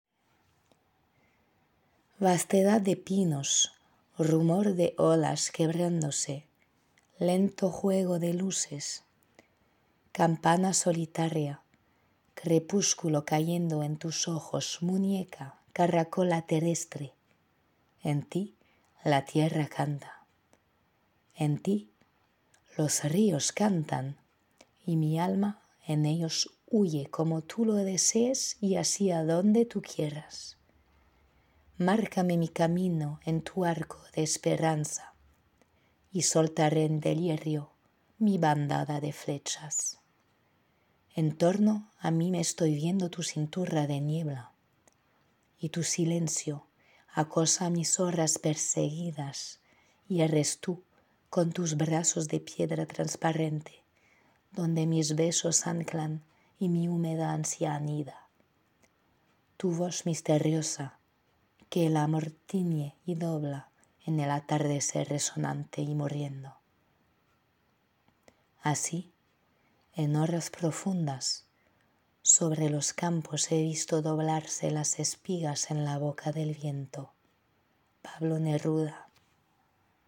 Bandes-son
Poème en espagnol